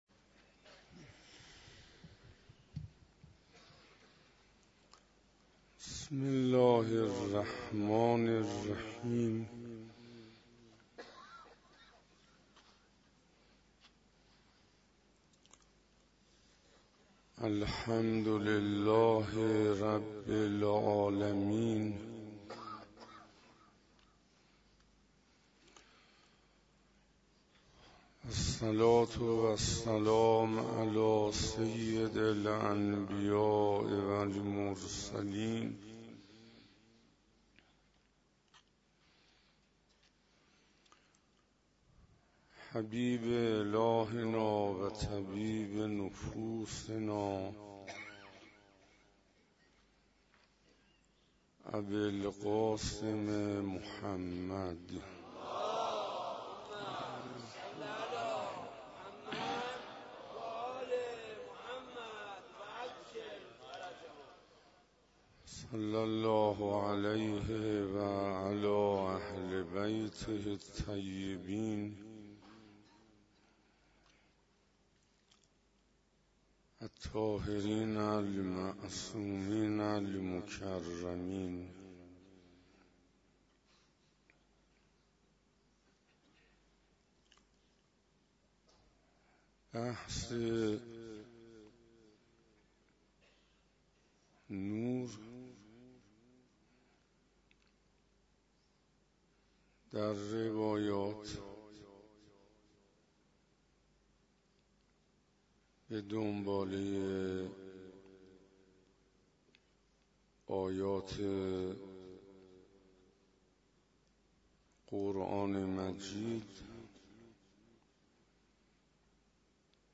روز ششم محرم 97 - حسینیه هدایت - عشق حقیقی